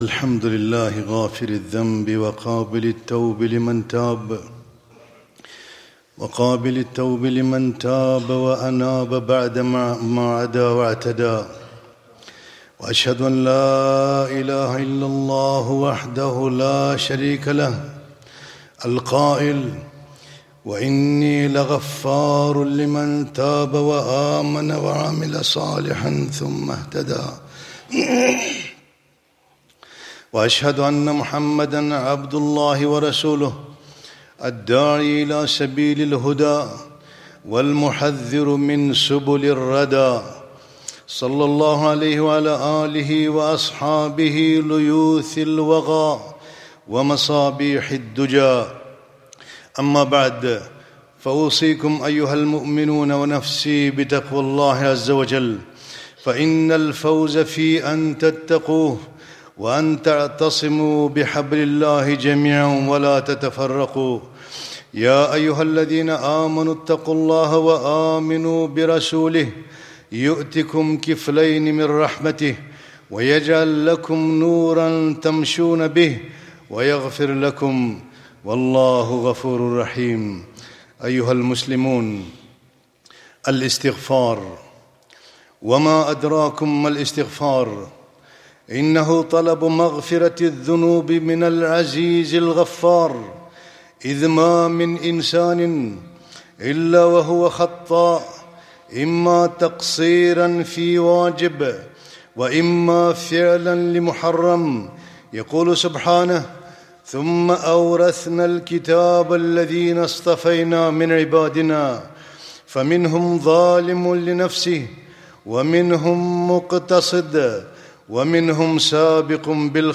خطبة - لولا تستغفرون الله لعلكم ترحمون